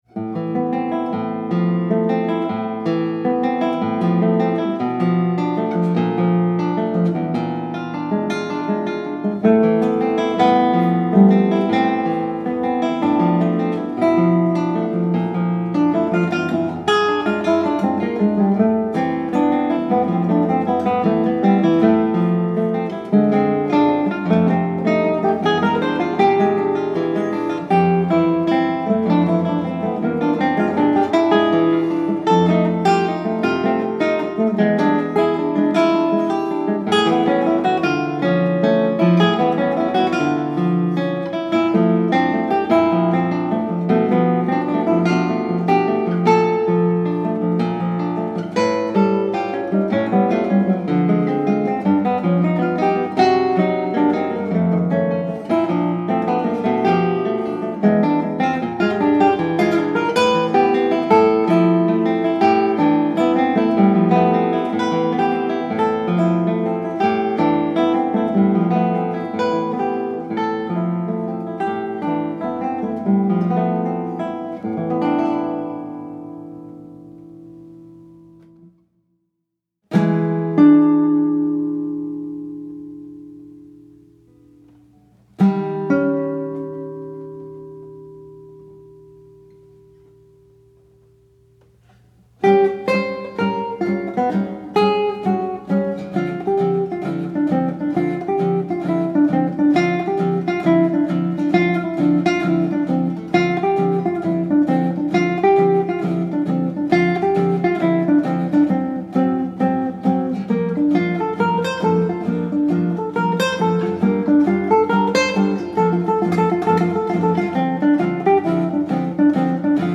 for Two Guitars or Flute and Guitar